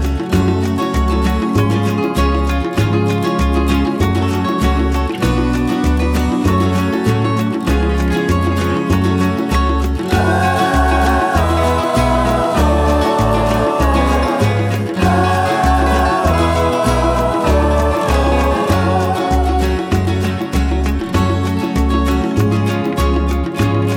Comedy/Novelty